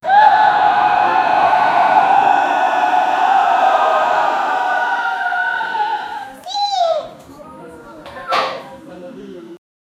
Distress Sound Effects - Free AI Generator & Downloads
someone-trying-to-suicide-lr4tvqur.wav